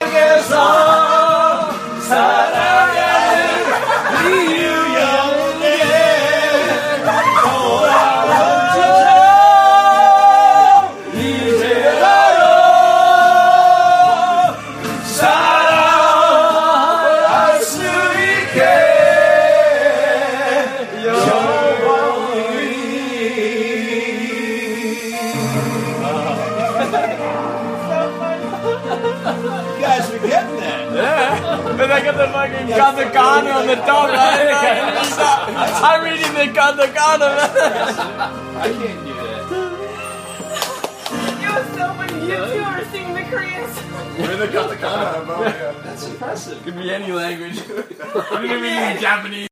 标签： 气氛 音乐 fieldrecording 歌曲 街道
声道立体声